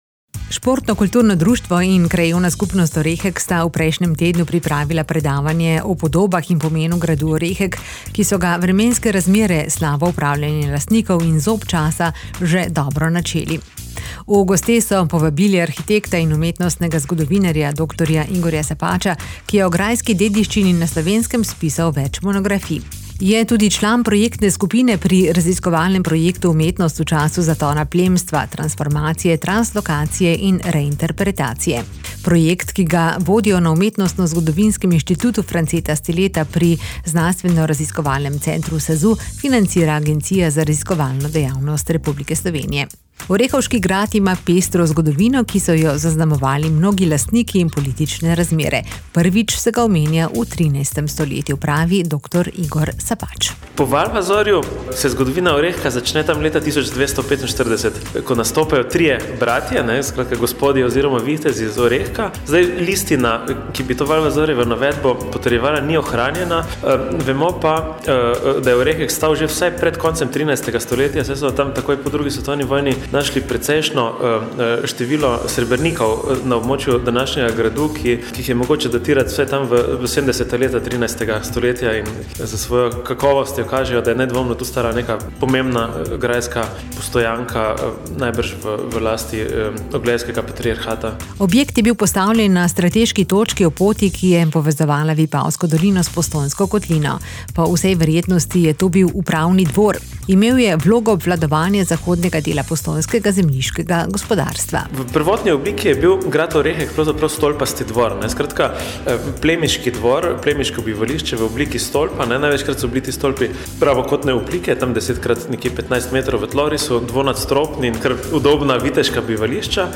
Športno kulturno društvo in Krajevna skupnost Orehek sta nedavno pripravila predavanje o podobah in pomenu gradu Orehek, ki so ga vremenske razmere, slabo upravljanje lastnikov in zob časa že dobro načeli.